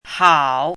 chinese-voice - 汉字语音库
hao3.mp3